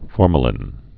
(fôrmə-lĭn)